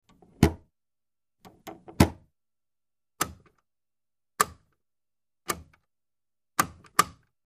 Air Conditioner; Buttons & Vents; Several Buttons Being Pushed; First Two Are Pressed Heavier And Sounds Duller Than The Rest. Close Perspective.